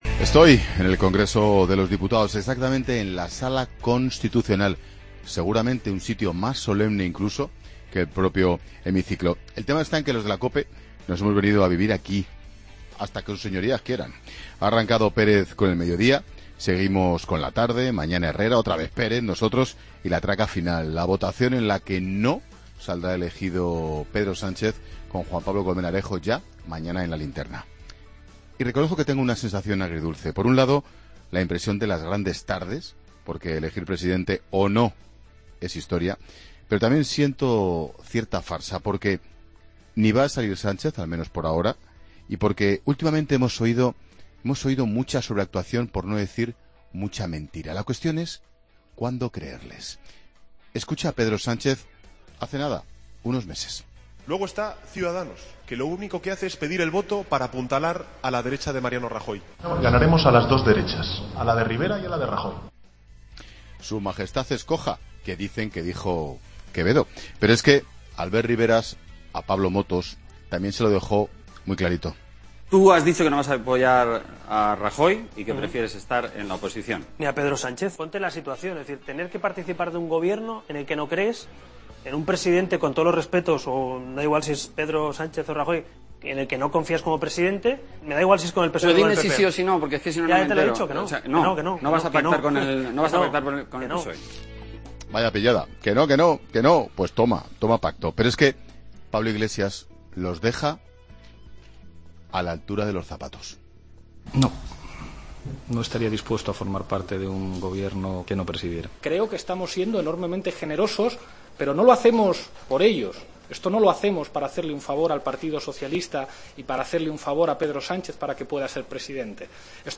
Asi ha arrancado Ángel Expósito desde el Congreso el Especial Investidura de COPE